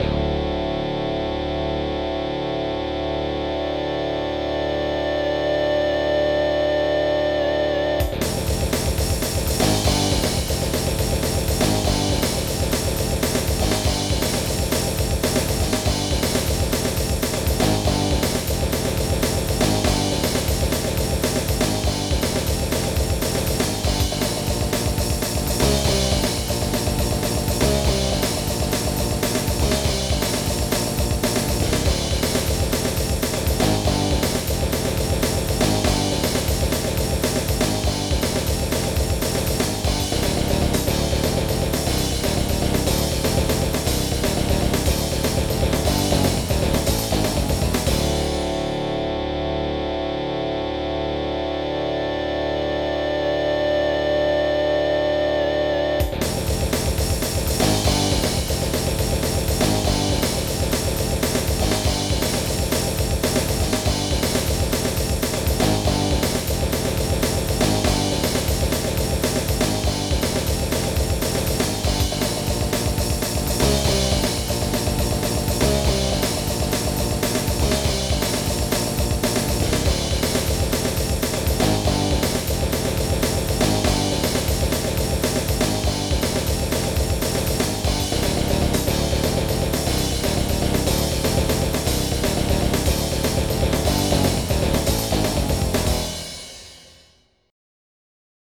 2 channels